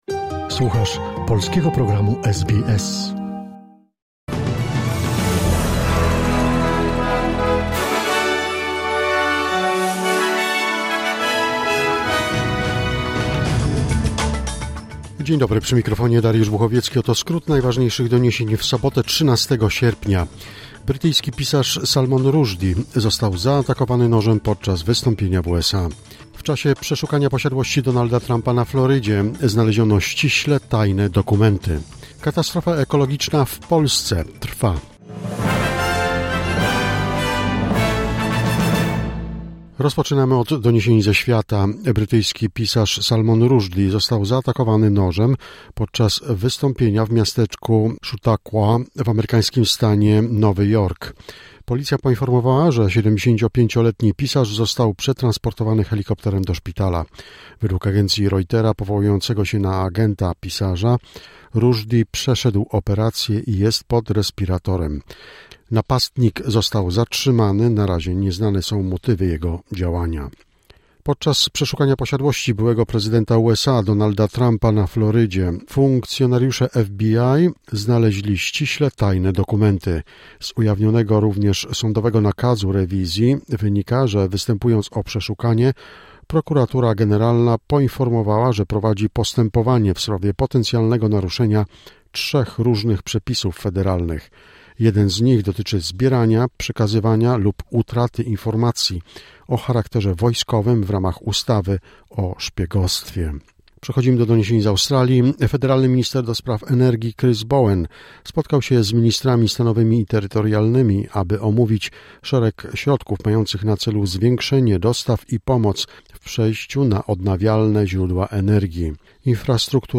SBS Flash News in Polish, 11 August 2022